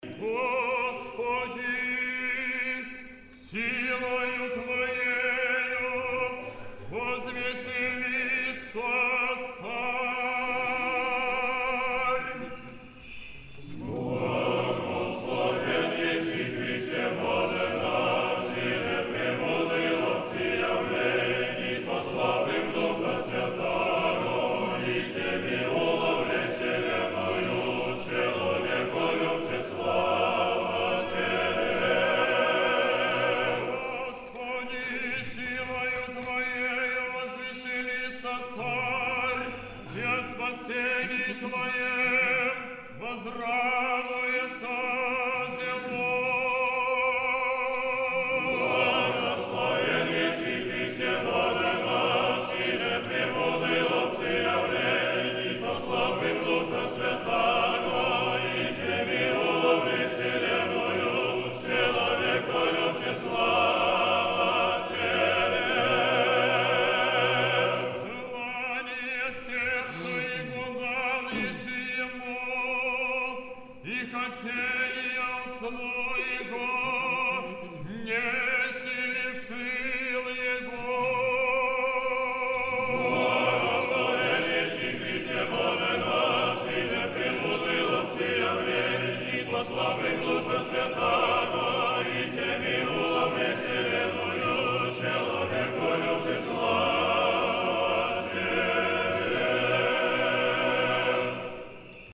Песнопения праздника Троицы
Антифон 3, псалом 20. Хор Московского Сретенского монастыря
Antifon_3-8d99d6.mp3